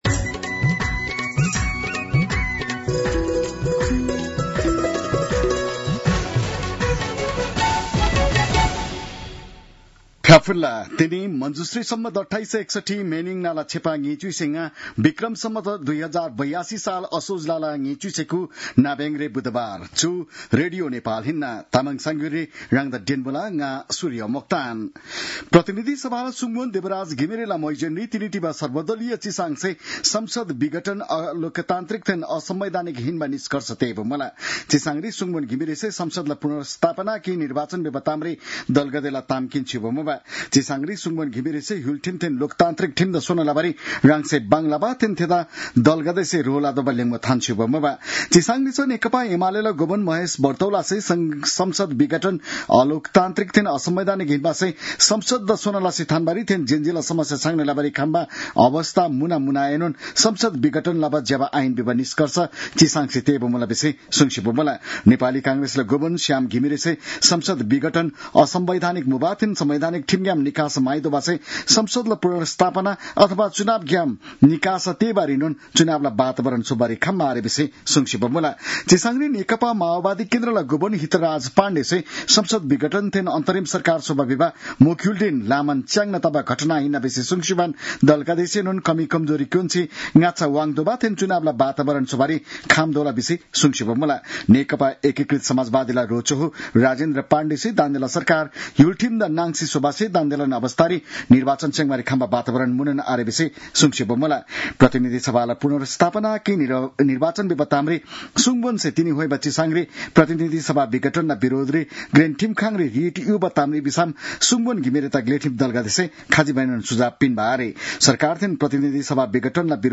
तामाङ भाषाको समाचार : २९ असोज , २०८२